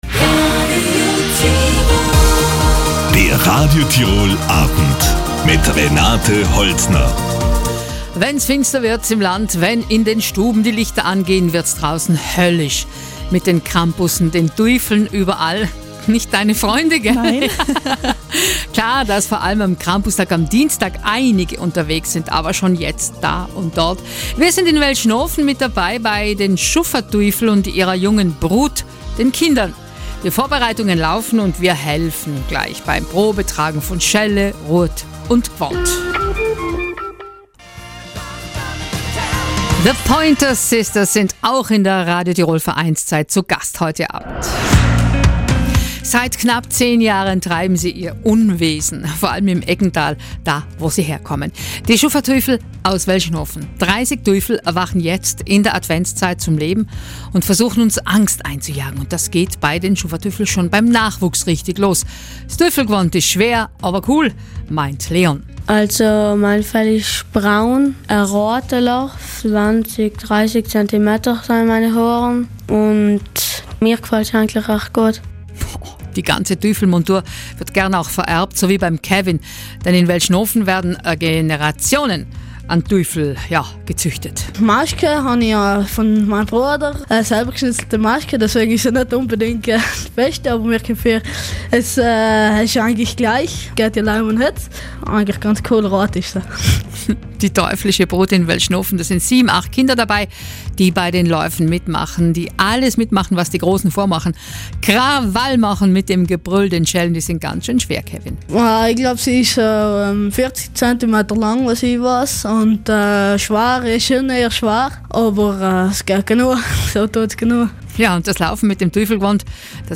Und das geht bei den Schuffa Tuifl schon beim Nachwuchs richtig los, das Tuiflgwond ist schwer, aber cool, sagen die Kids. Die teuflische Brut in Welschnofen, da sind 7-8 Kinder dabei, die bei den Läufen mitmachen, die alles mitmachen was die Großen vormachen, Krach machen mit dem Gebrüll und den Schellen.
RT_Verein_Krampus_SchuffaTuifl_Welschnofen_Kids.MP3